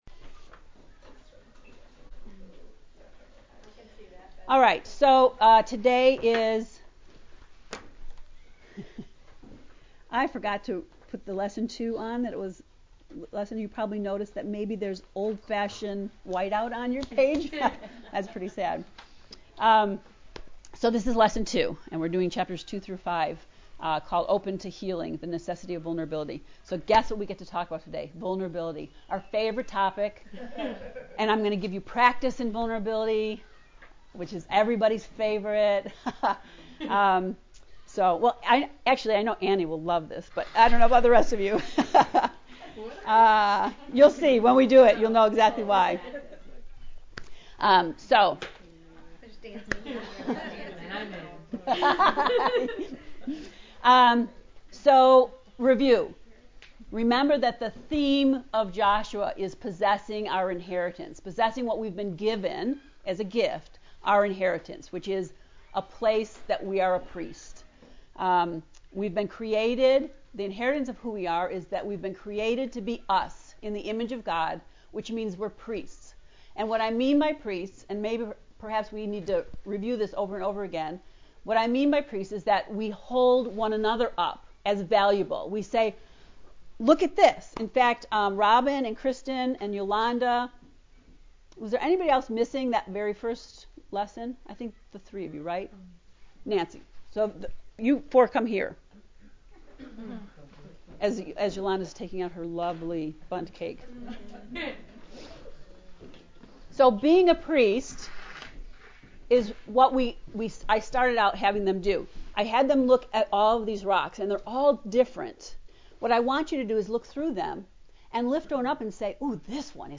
JOSHUA lesson 2